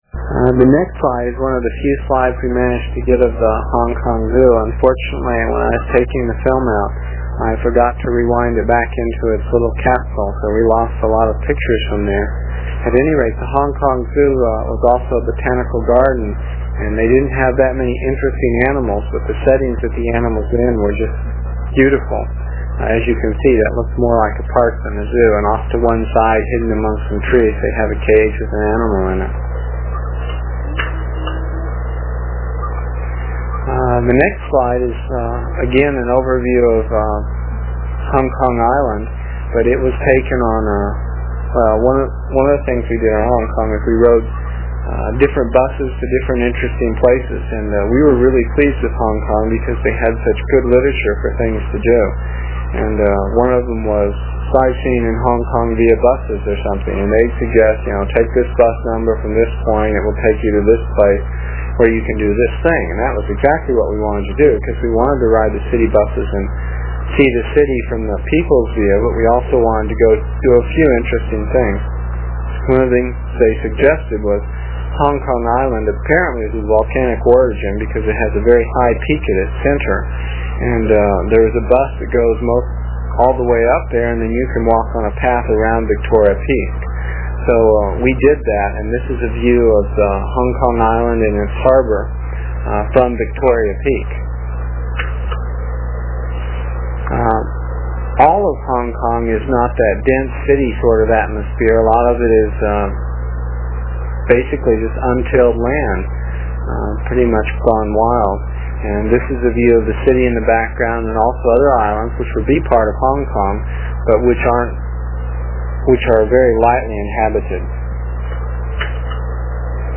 There is a voice description of each slide for the first part and
It is from the cassette tapes we made almost thirty years ago. I was pretty long winded (no rehearsals or editting and tapes were cheap) and each section for this page is about four minutes and will take about a minute to download with a dial up connection.